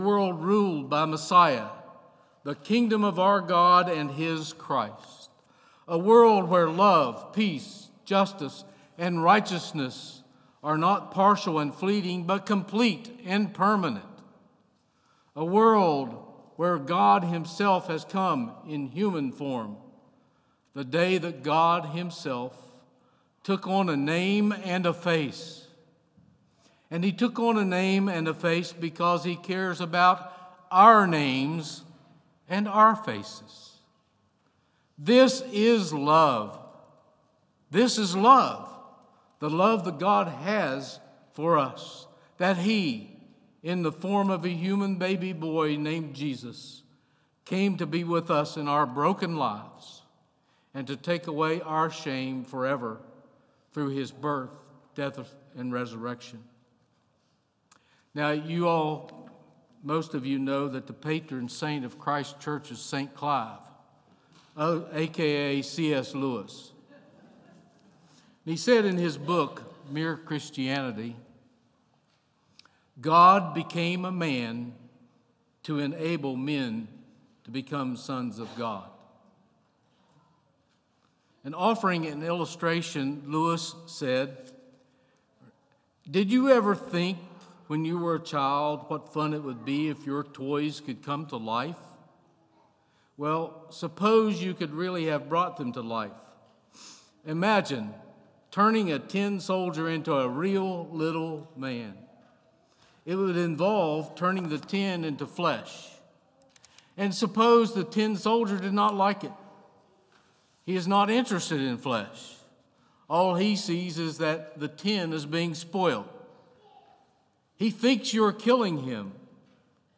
Christmas Day (partial sermon)